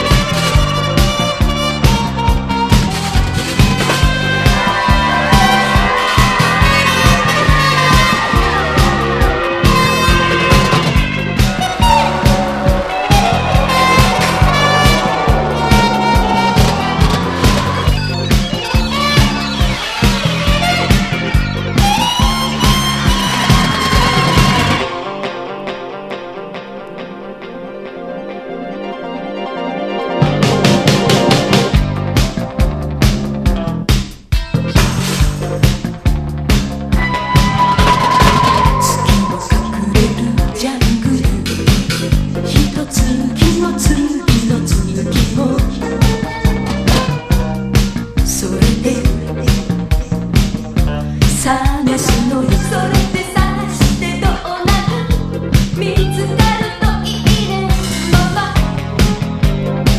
JAPANESE NEW WAVE / JAPANESE PUNK